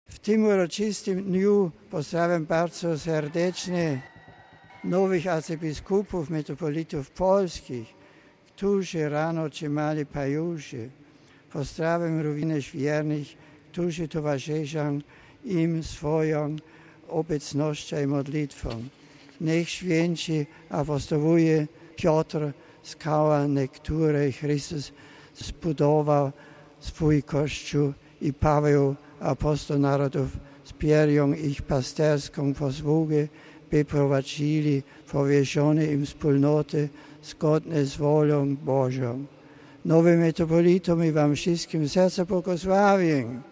Po modlitwie Anioł Pański Papież zwrócił się także do nowych polskich metropolitów.